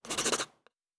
Writing 9.wav